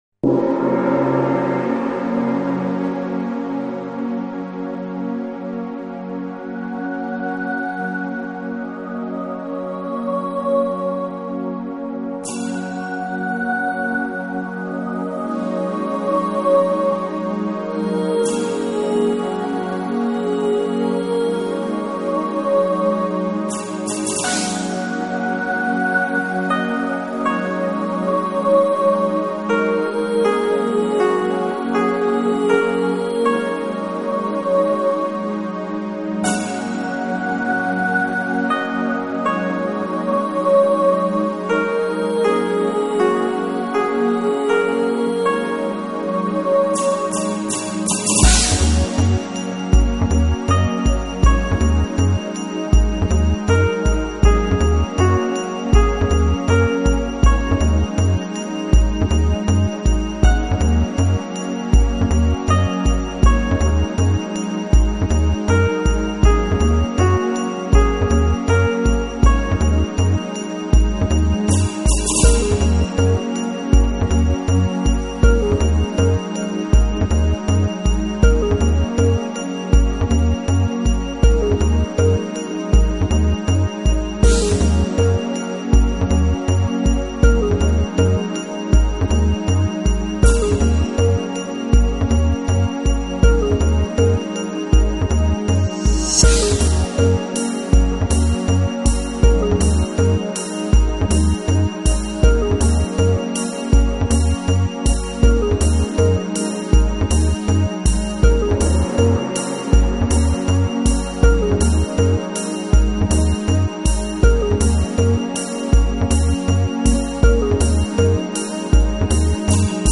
悠扬舒缓，或扬或抑的东方旋律，让你的灵魂得到宁静。
中国十三弦古筝和长笛的天籁之音变幻出一个深邃广袤的周围空间，引领你进入一个宇宙般的世界，在这里，你的灵魂受到震撼。
之前发帖找这个专辑，这个应该是原版，演奏手法有些不同，带有西方味道,一样动听